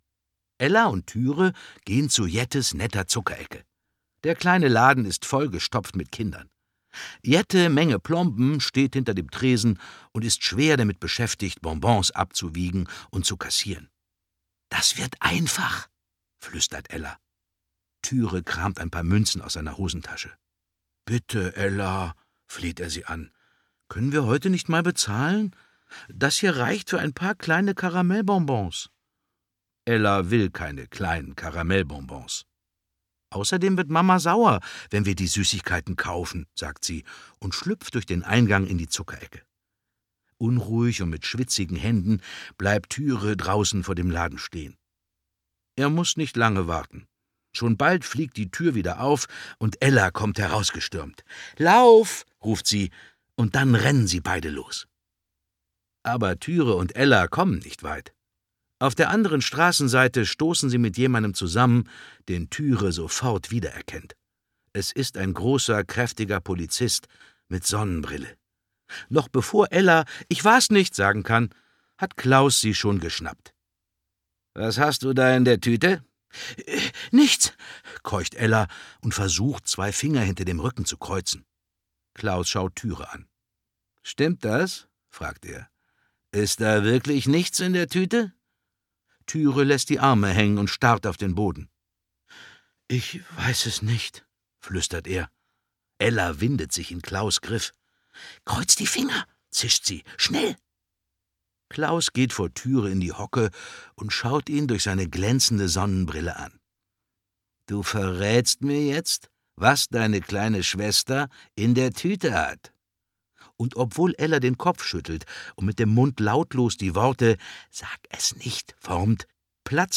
Familie von Stibitz - Ein hundsgemeiner Polizist Anders Sparring , Per Gustavsson (Autoren) Dietmar Bär (Sprecher) Audio-CD 2020 | 1.